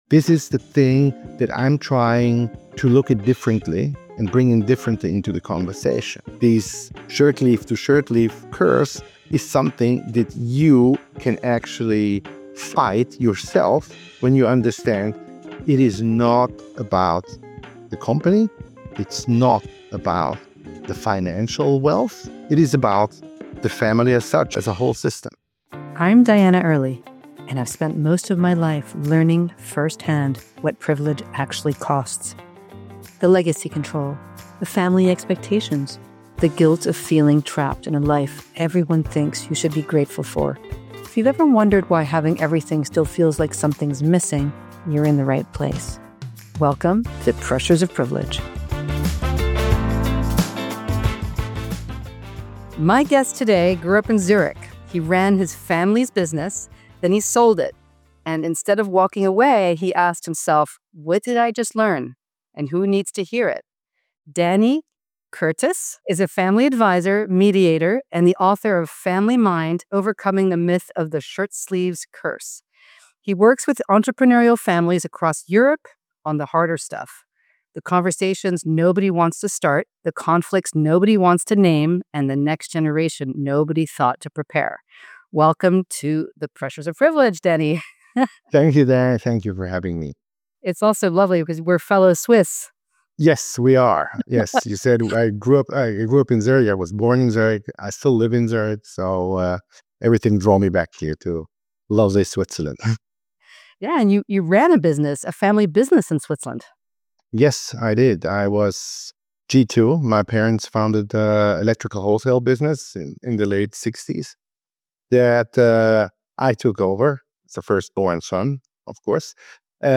for a conversation that goes far deeper than succession planning.